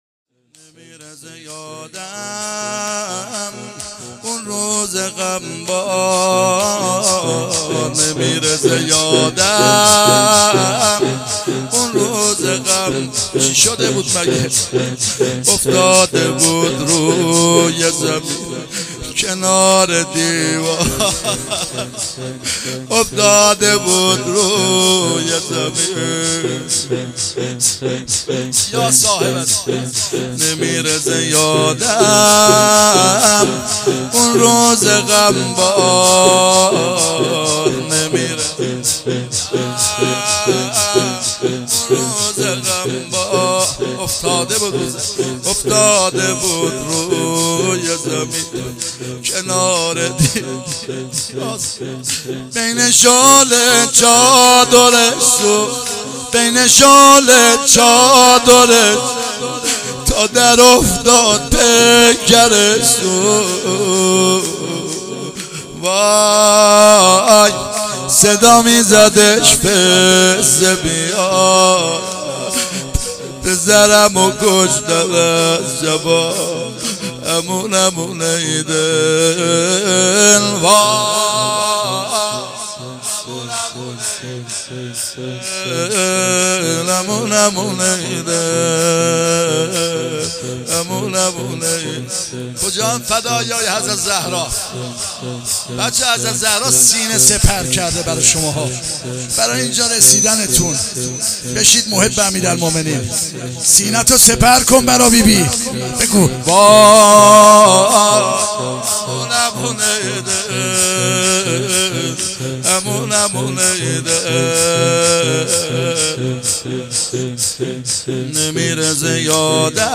شور | نمیره ز یادم اون روز غمبار
مداحی
در شب اول فاطمیه دوم 1397